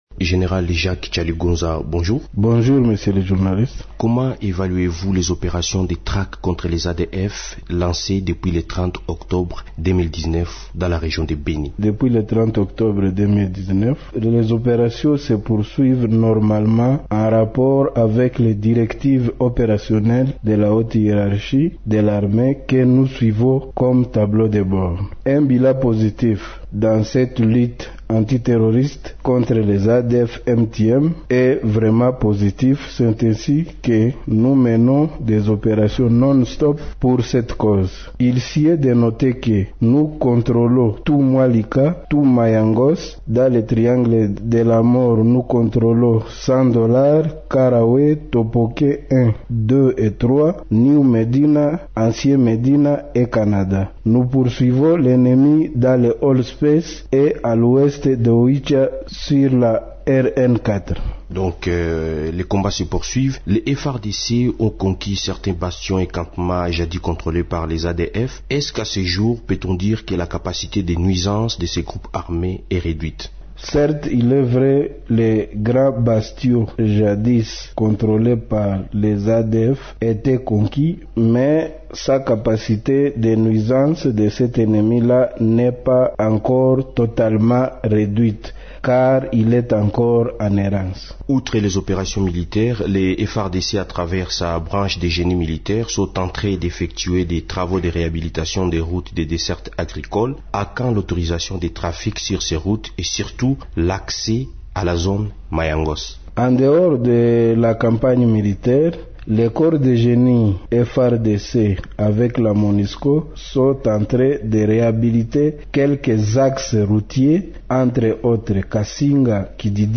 Dans une interview exclusive accordée mercredi 22 janvier à Radio Okapi, le commandant du secteur opérationnel Sokola 1 Grand Nord, général de brigade Jacques Chaligonza, a déclaré que depuis le lancement de l’opération dénommée « Libérez Mayangose » pour neutraliser les rebelles des ADF, plusieurs bastions ennemis ont été conquis par les FARDC.